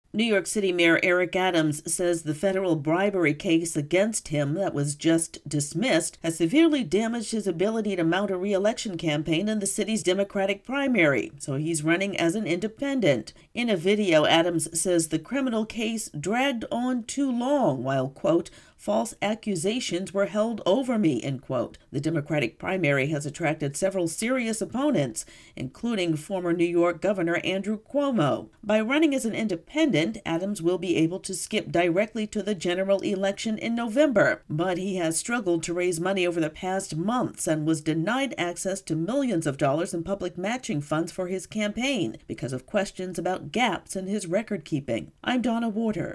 New York Mayor Eric Adams says he'll be running for re-election as an independent. AP correspondent